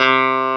CLAV2HRDC3.wav